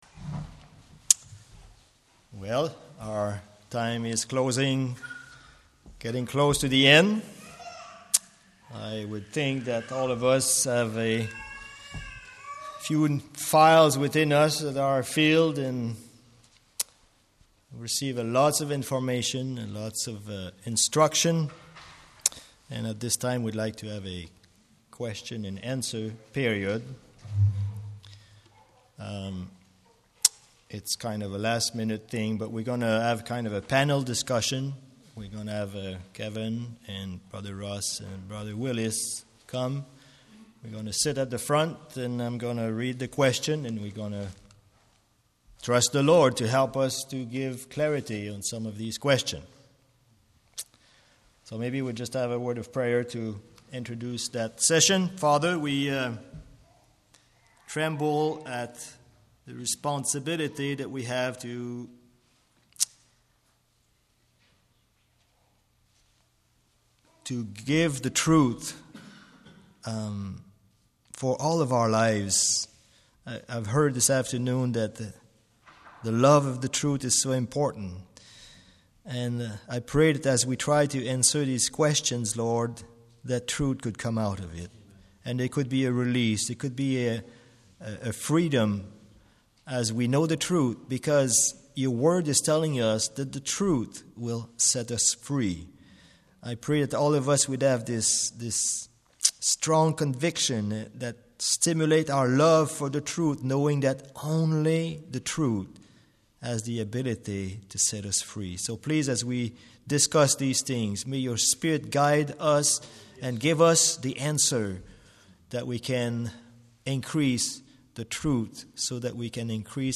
Service Type: Ladies Seminar, Men's Seminar